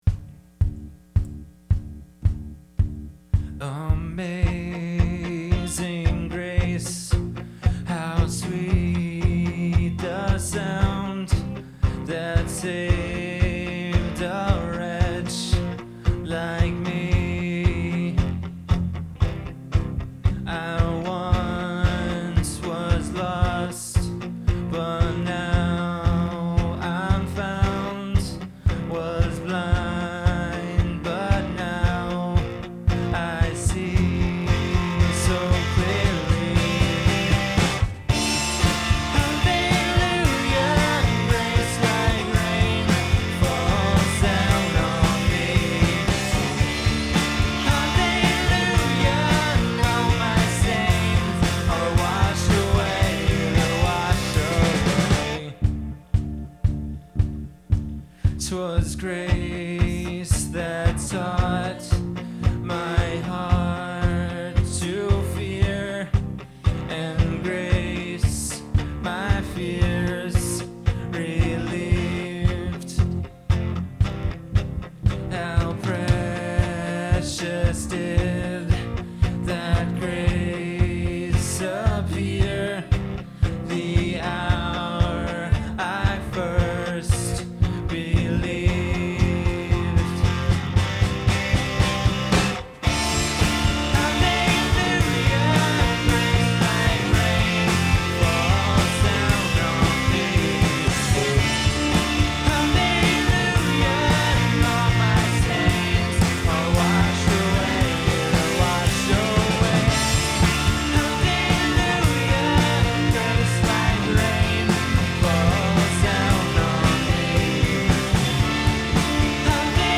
Performed live at Terra Nova - Troy on 11/22/09.